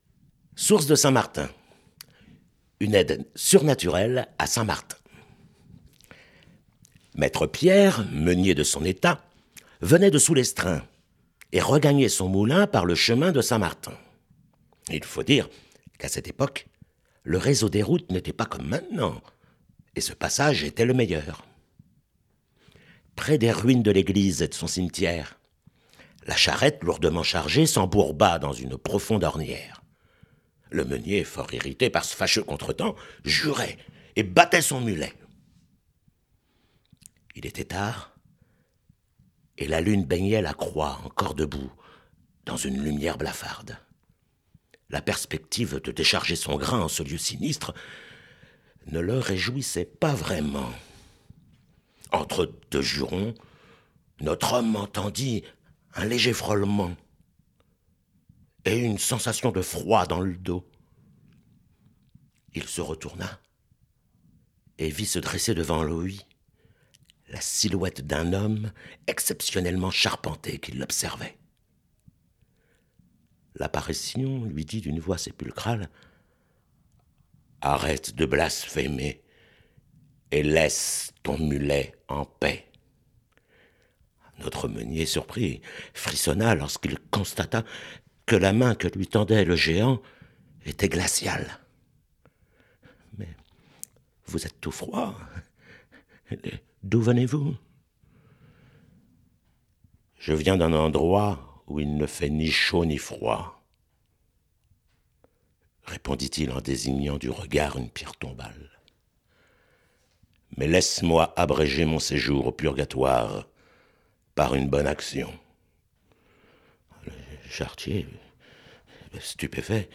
LegendeStMartin.mp3